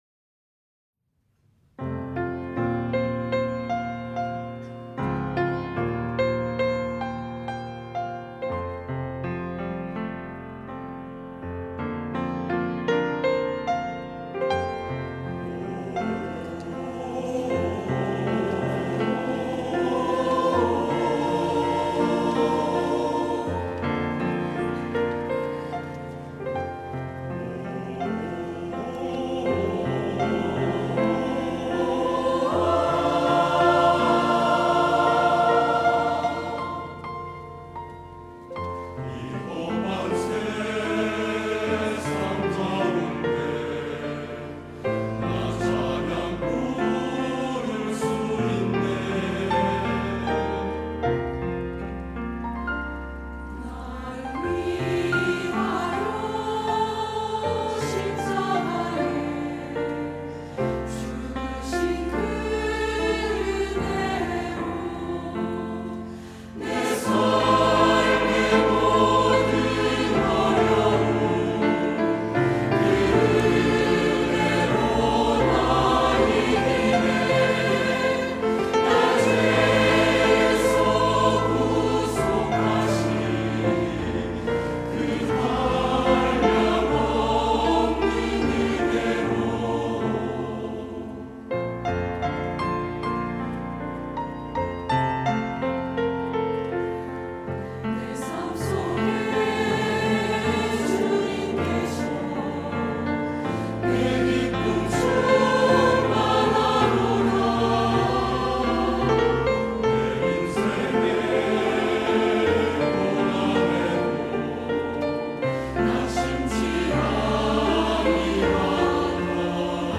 할렐루야(주일2부) - 내 삶엔 찬양뿐이네
찬양대